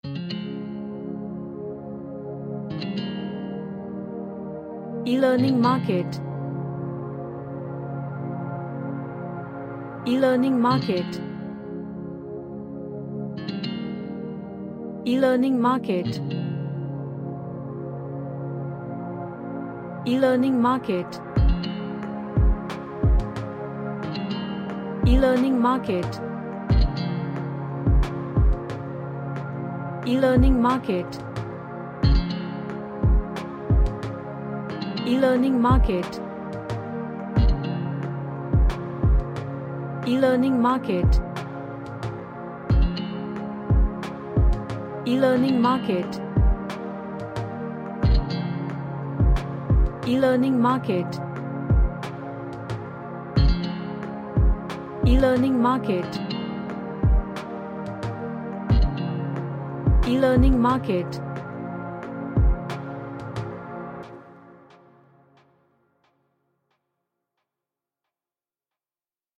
A Relaxing Track with Guitar Strums.
Relaxation / Meditation